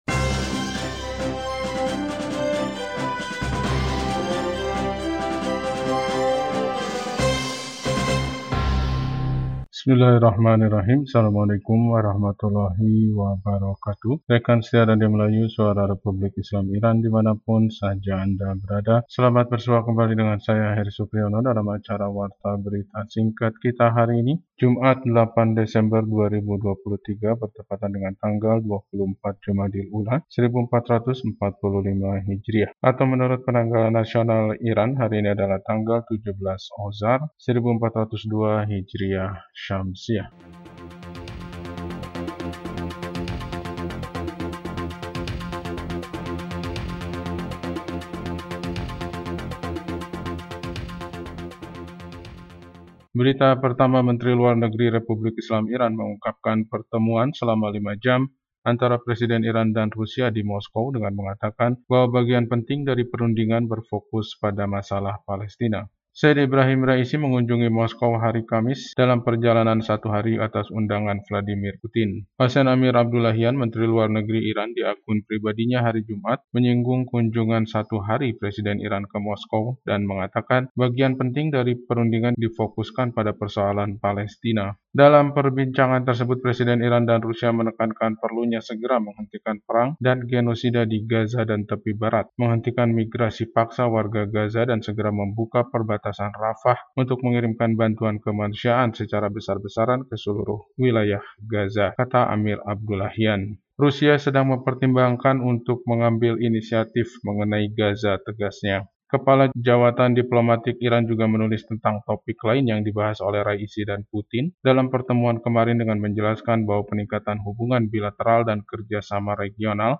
Warta Berita 8 Desember 2023